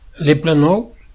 Prononciation occitane : La Plaine 00:00 / 00:00 Les Planols 00:00 / 00:00 Article sur ''Le Plo, Les Plos'' Sommaire